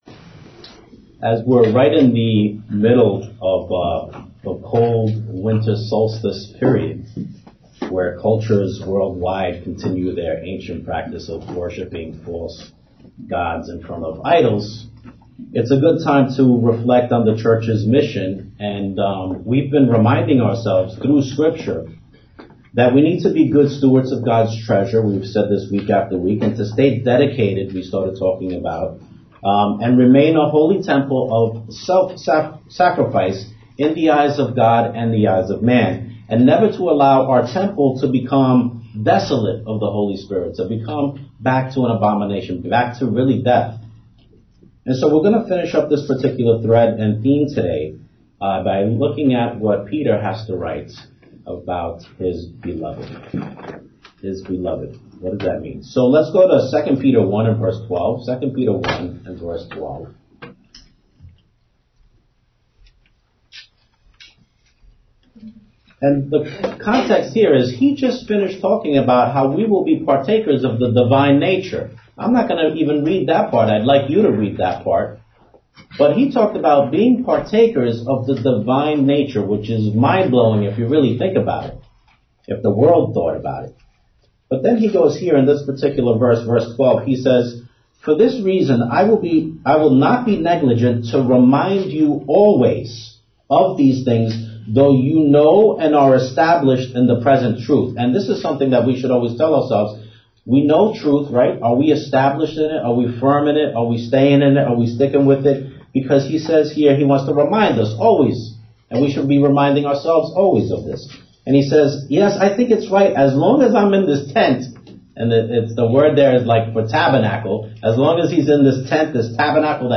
Sermons
Given in New Jersey - North New York City, NY